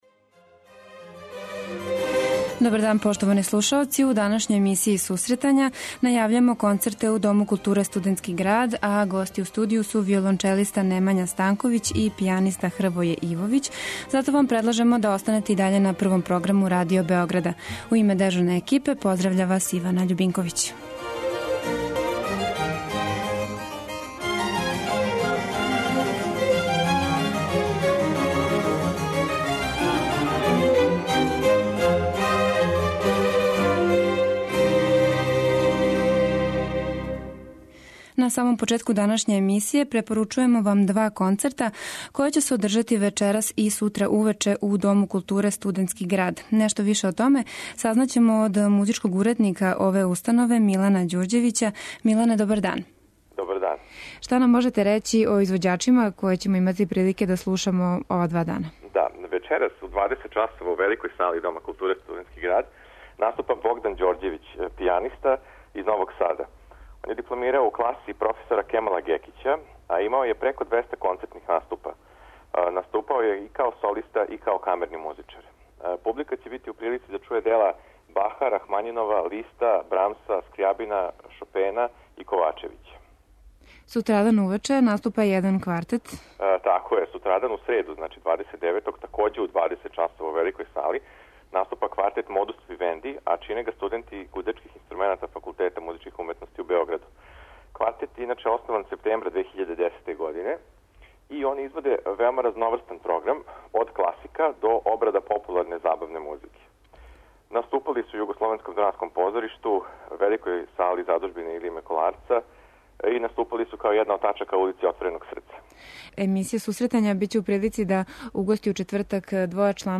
преузми : 26.14 MB Сусретања Autor: Музичка редакција Емисија за оне који воле уметничку музику.